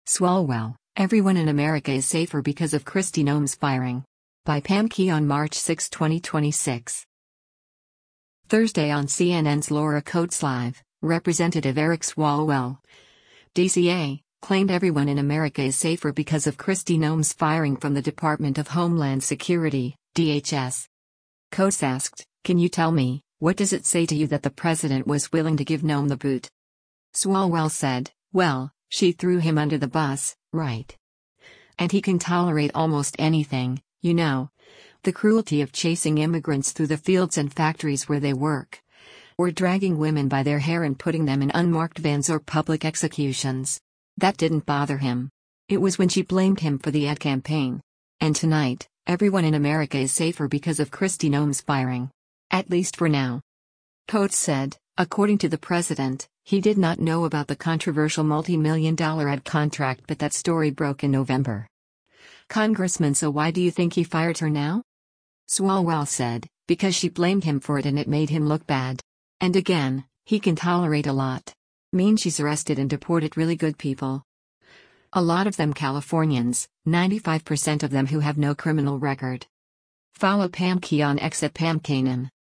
Thursday on CNN’s “Laura Coates Live,” Rep. Eric Swalwell (D-CA) claimed “everyone in America is safer because of Kristi Noem’s firing” from the Department of Homeland Security (DHS).